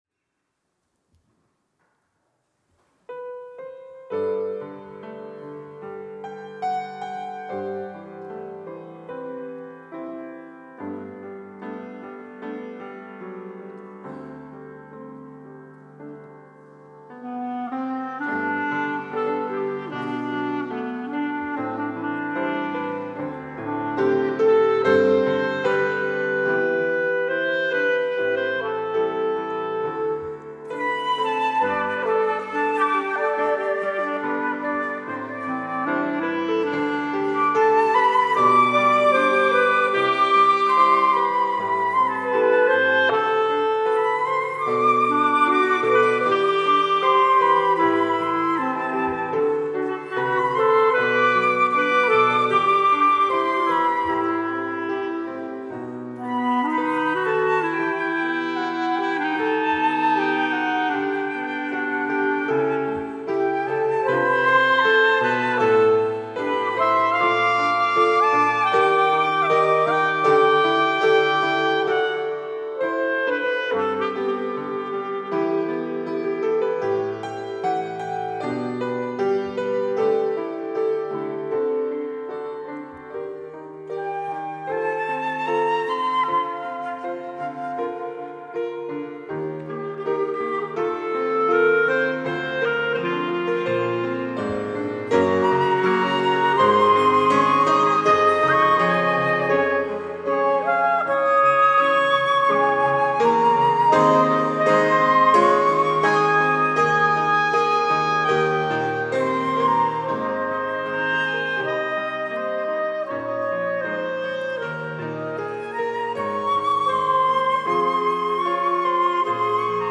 특송과 특주 - 청년부 특송